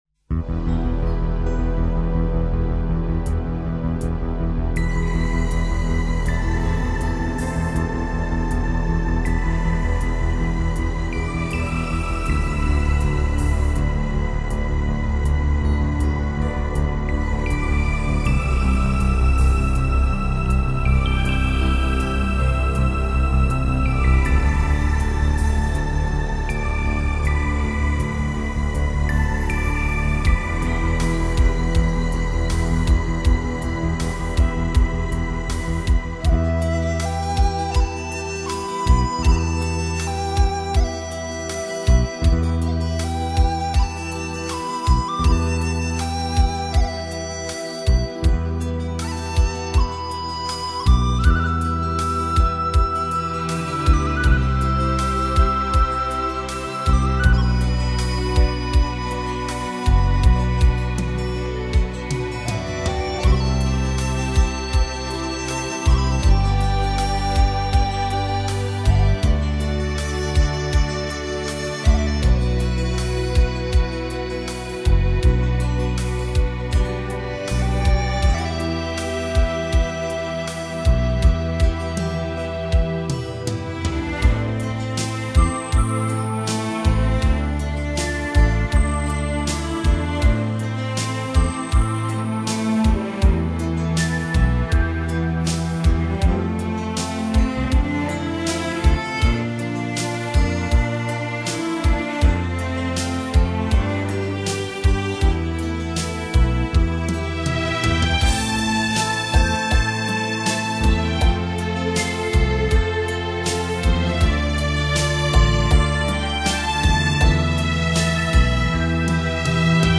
愿这高亢嘹亮、缠绵优美、欢快轻松的民歌专辑，能使阁下更
伊克昭盟民歌
远处传来一阵阵牧笛声，还有马头琴
在轻轻吟唱。